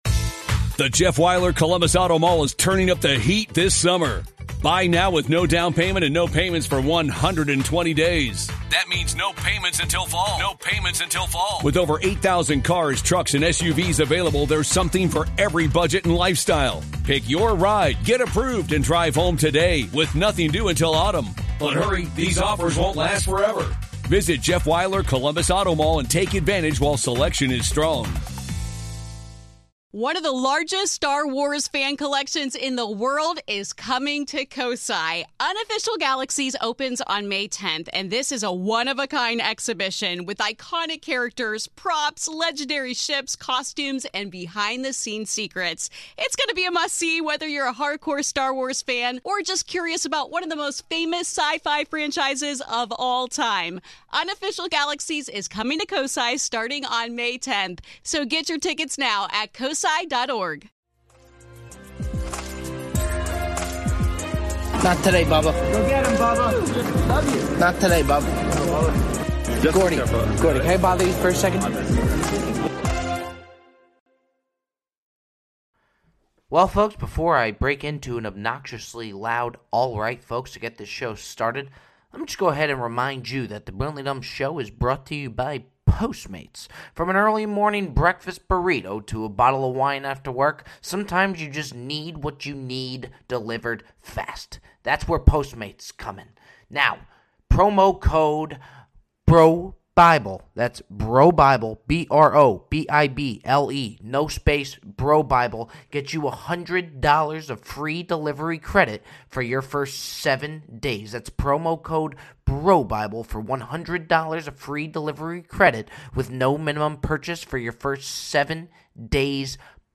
"Animal Planet" Host joins the show to give his thoughts on the new Netflix hit documentary: "Tiger King"